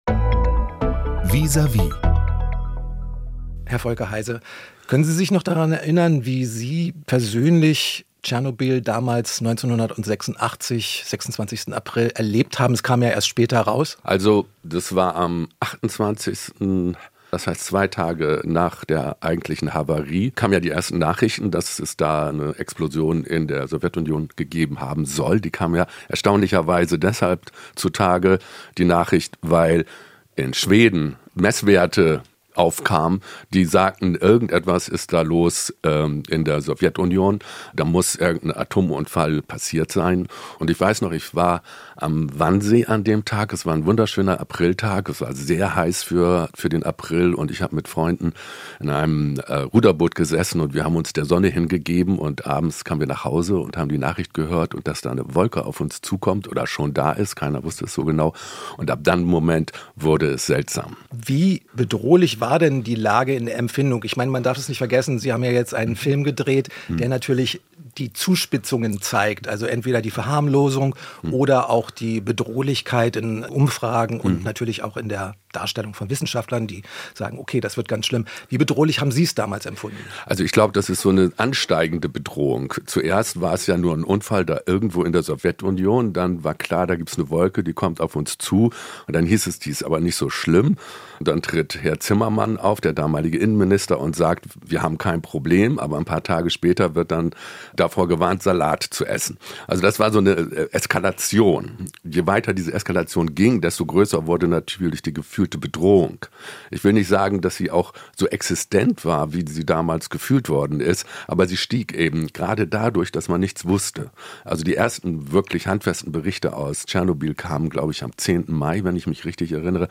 erzählt er im Gespräch